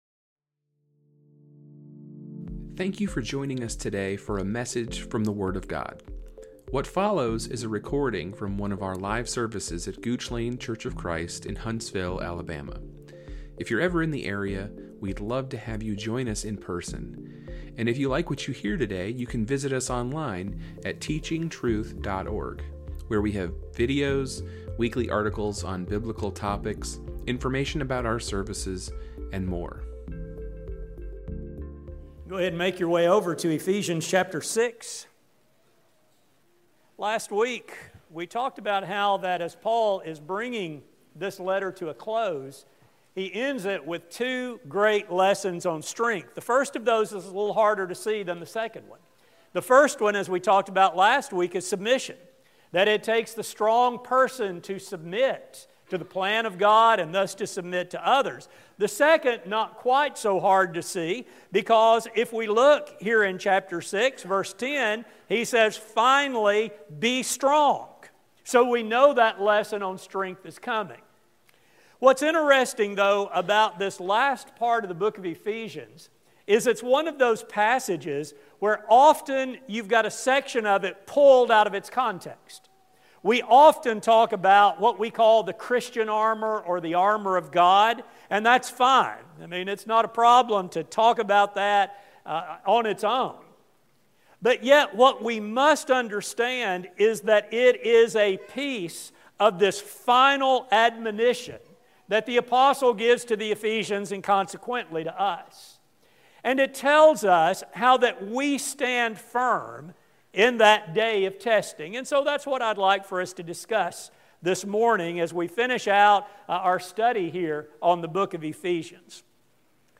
This study will focus on the sobriety every child of God must have, and the great strength God promises for our victory. A sermon